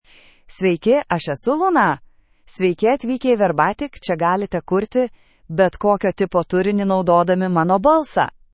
LunaFemale Lithuanian AI voice
Luna is a female AI voice for Lithuanian (Lithuania).
Voice sample
Listen to Luna's female Lithuanian voice.
Female
Luna delivers clear pronunciation with authentic Lithuania Lithuanian intonation, making your content sound professionally produced.